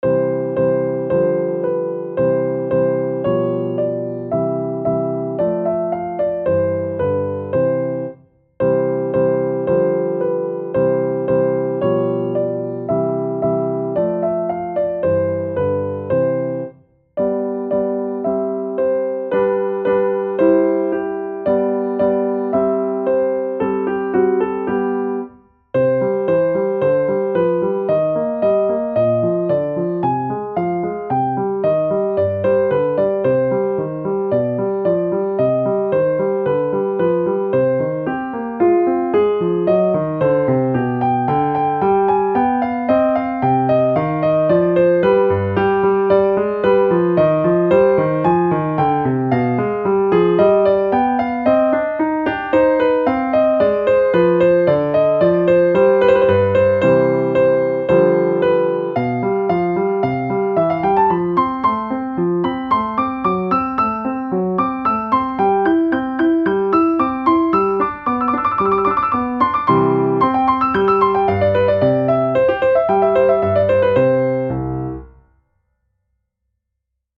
My variations on Diabelli's Allegretto in C - Piano Music, Solo Keyboard - Young Composers Music Forum